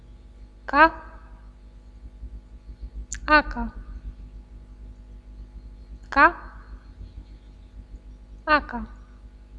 Voiced Sounds of the Romanian Language
Consonants - Speaker #3